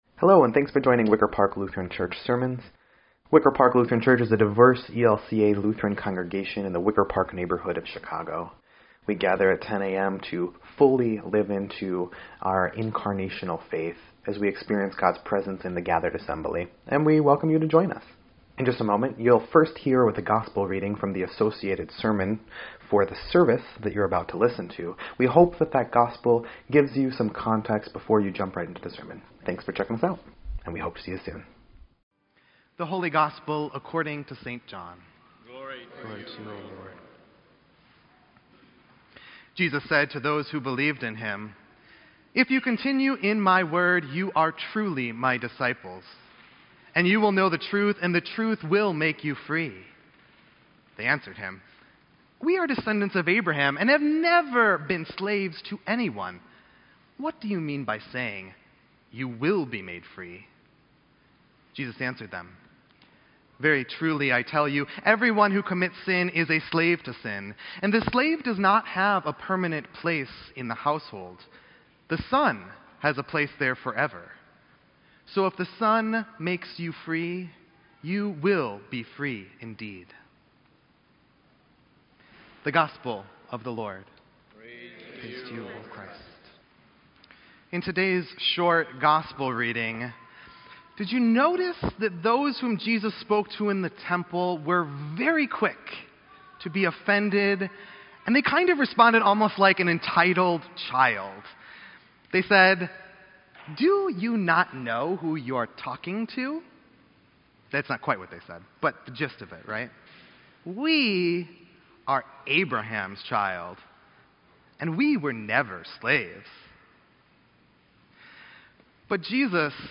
EDIT_Sermon_10_28_18-2.mp3